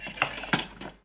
Meter pan en una tostadora
tostadora
Sonidos: Acciones humanas
Sonidos: Hogar